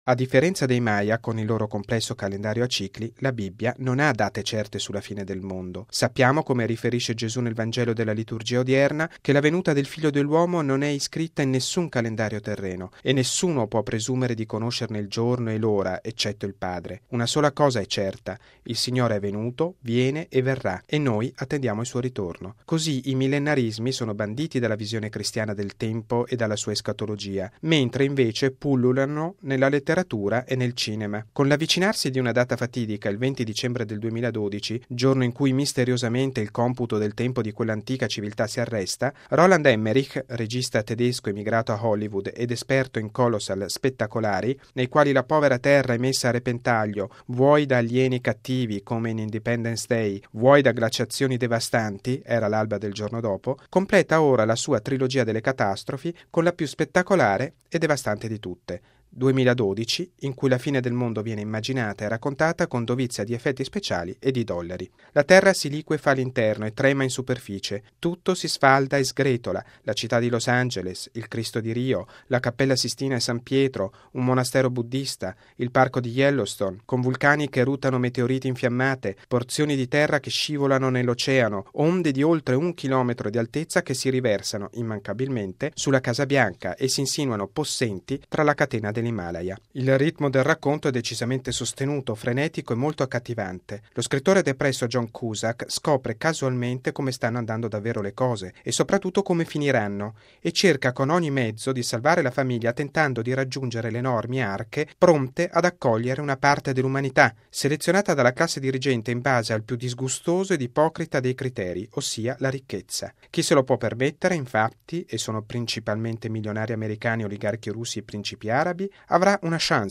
Uno spettacolo di grandi proporzioni, ricco di incredibili effetti speciali, che denuncia il peccato dell’umanità anche nel gestire gli ultimi tempi e la salvezza, spogliata di ogni dimensione biblica ed escatologica. Il servizio